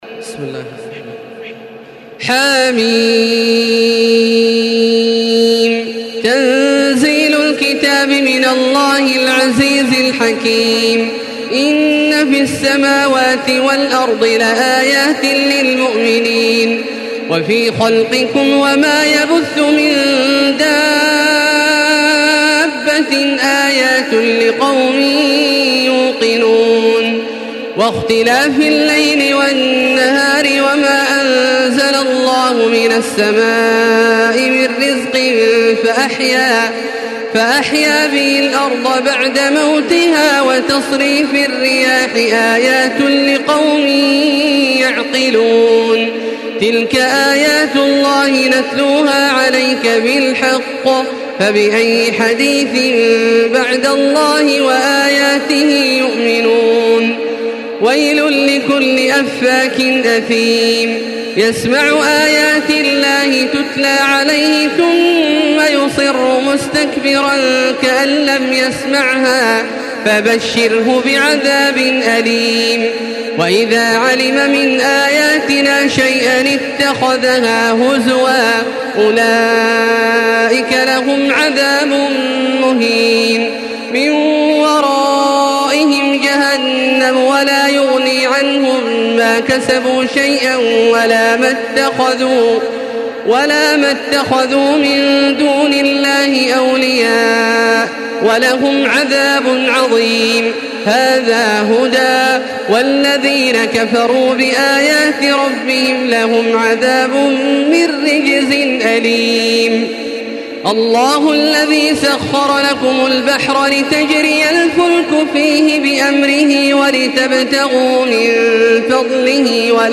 Listen and download the full recitation in MP3 format via direct and fast links in multiple qualities to your mobile phone.
تراويح الحرم المكي 1435
مرتل حفص عن عاصم